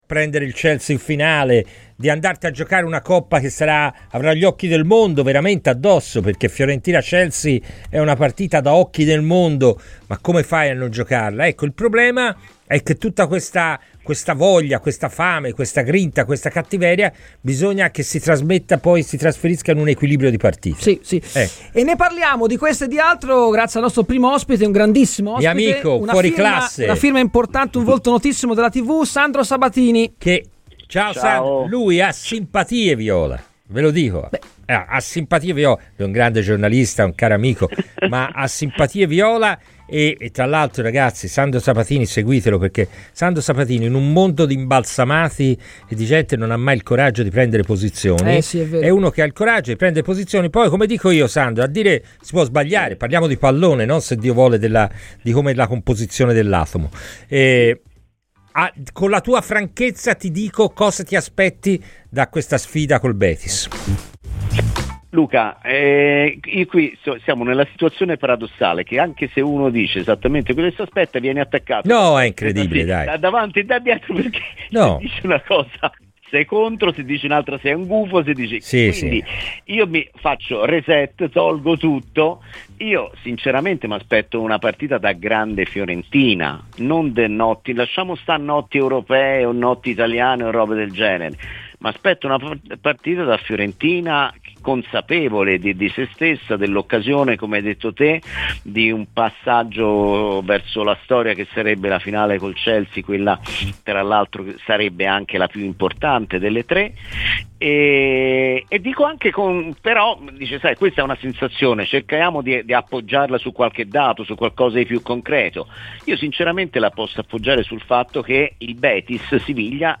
ha parlato ai microfoni di Radio FirenzeViola di Fiorentina-Real Betis